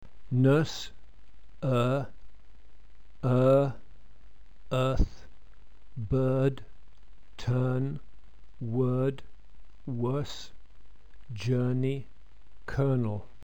English tense vowels
NURSE